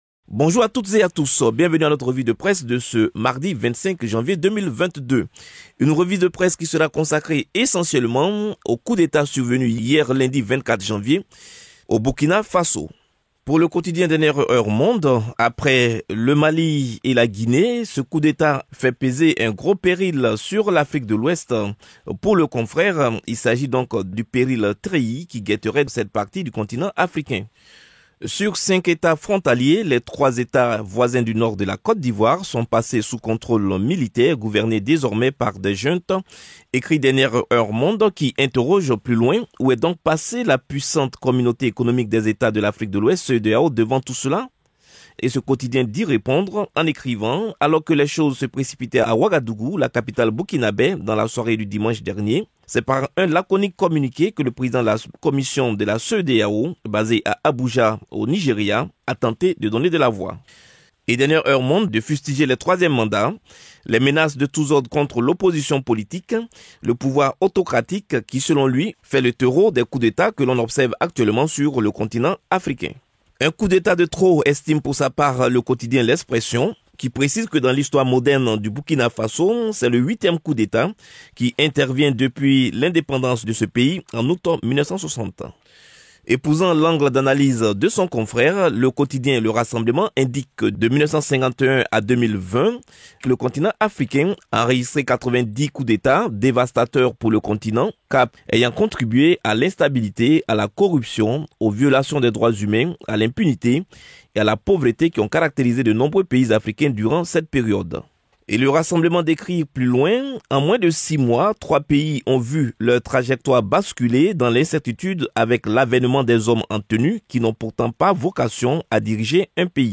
Revue de la presse ivoirienne datant du 25 janvier 2022. Explication des articles les plus importants concernant l'actualité ivoirienne.